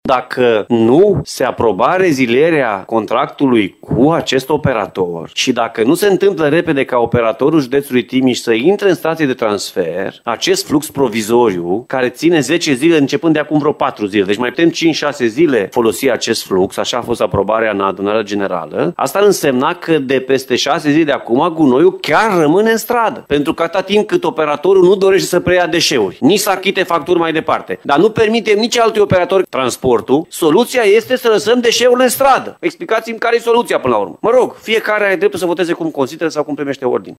Șapte dintre consilierii județeni s-au abținut de la vot, iar președintele CJ Timiș, Alfred Simonis, a declarat că dacă proiectul era respins, gunoaiele ar fi rămas în stradă.